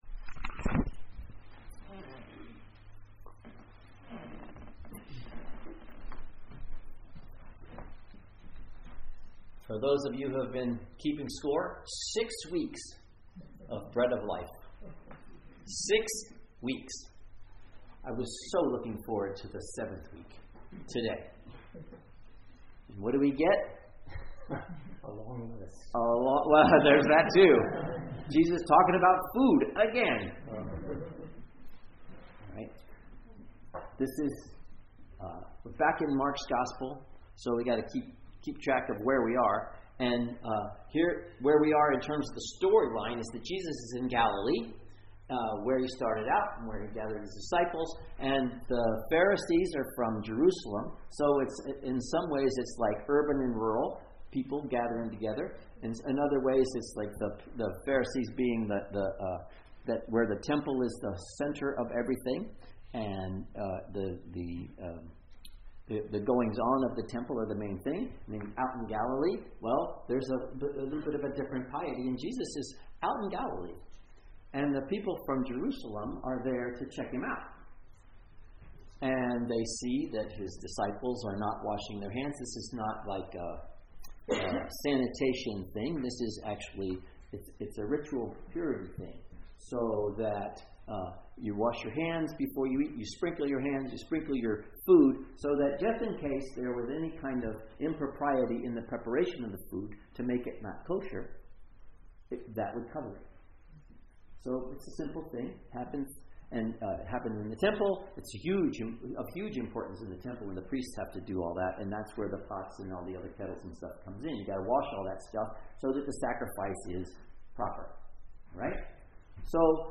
Sermons | Lake Chelan Lutheran Church
15th Sunday after Pentecost